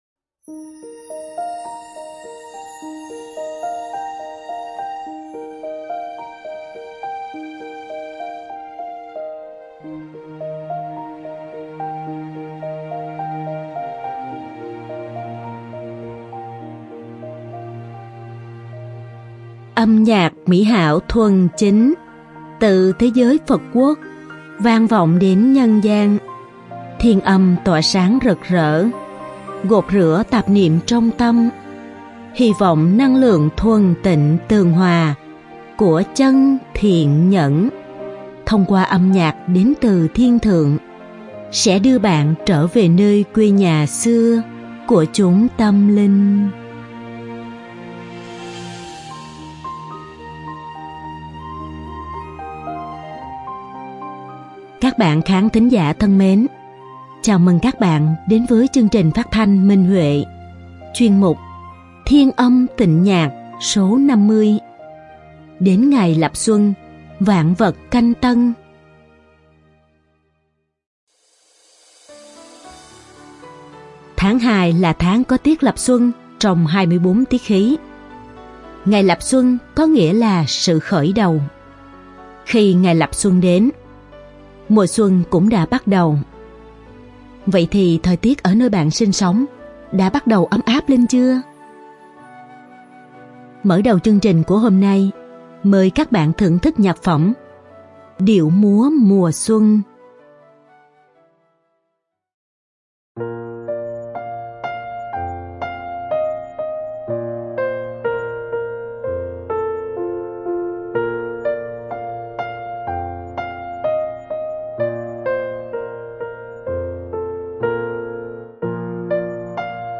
Song ca
Đơn ca nữ